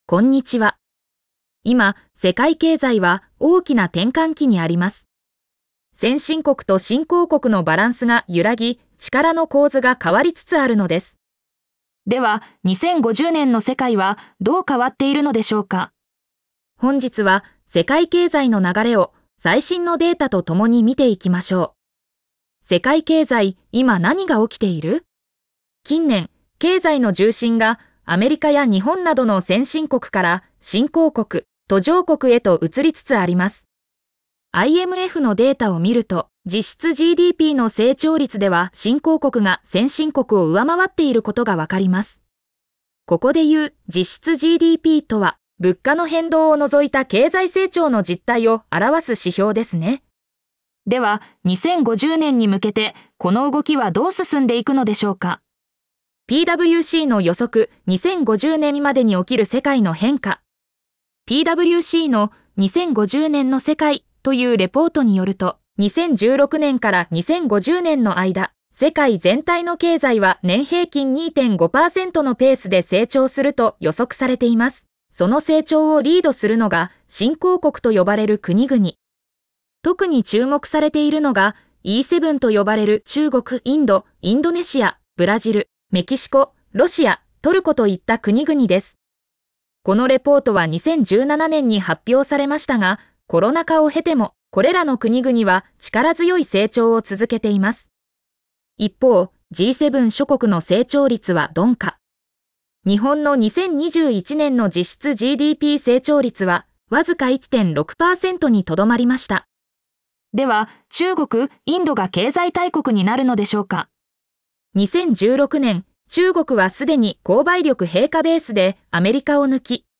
※こちらは音声で聞くことができるダイジェスト版です（約5分）。